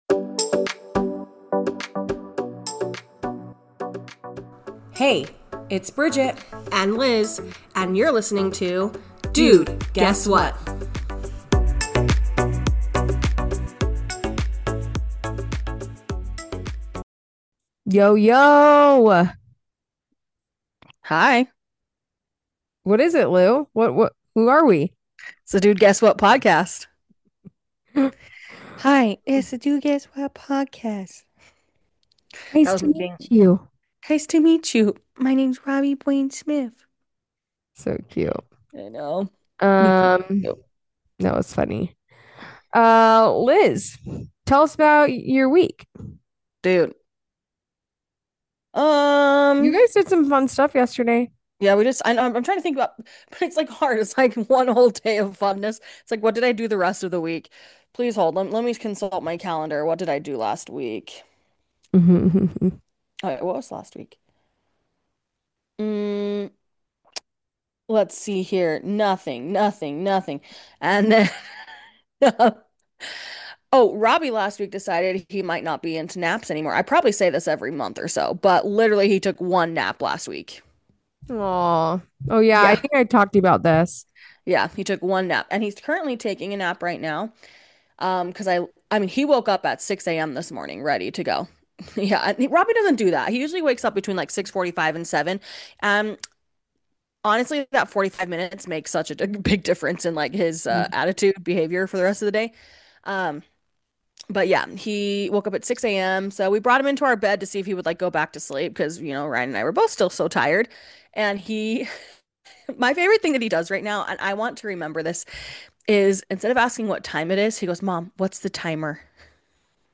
Just two sisters shootin' the breeze about anything and everything